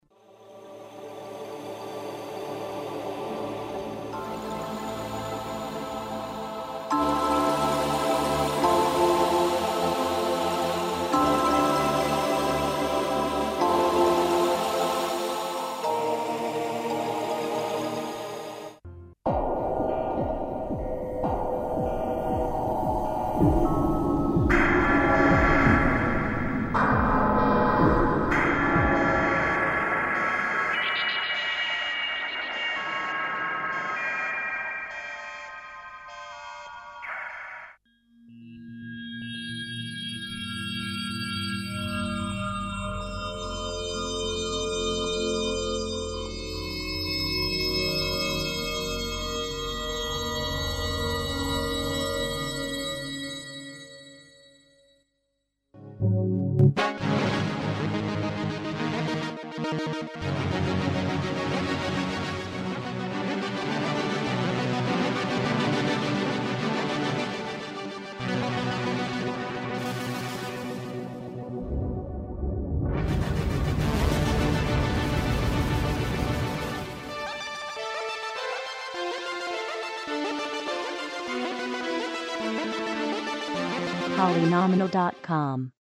various electro sounds